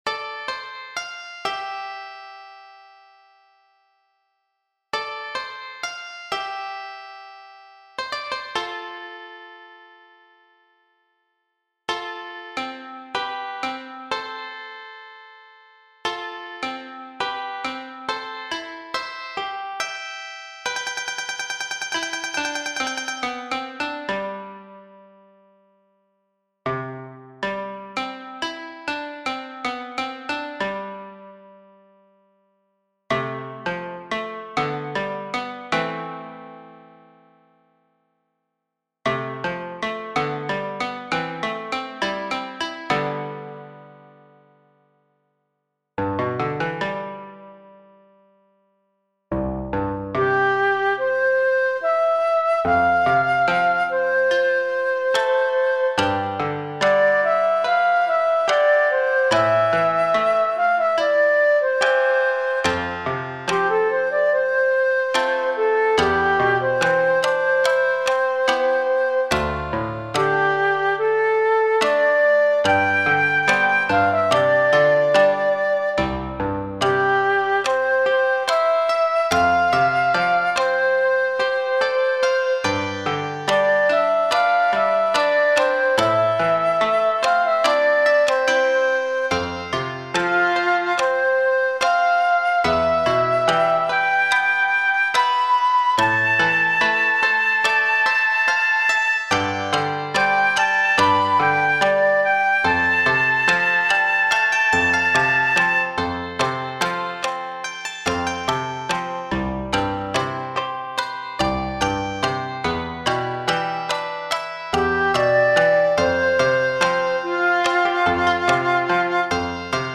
【編成】箏２・十七絃・尺八（一尺八寸）・三絃 光を映して、様々に色変わりする海。
静かな朝から、昼の喧騒、そして黄昏時へと繋がっていき、最後は夜の静寂の中、波の音だけが響き渡ります。